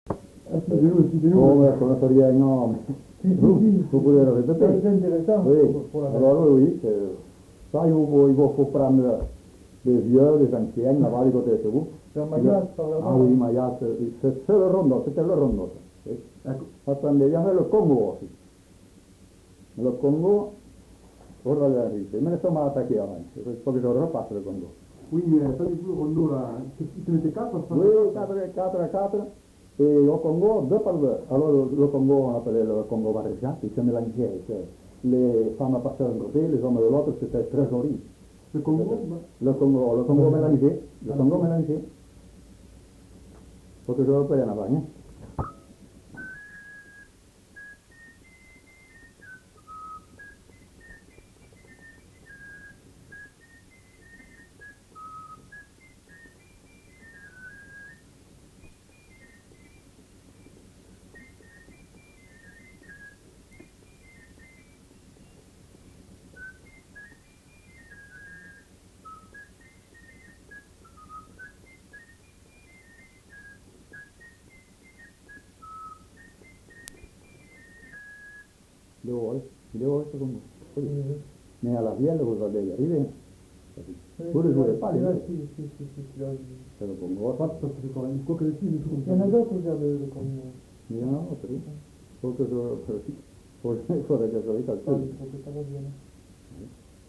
joué à la flûte de Pan et à l'harmonica
Rondeau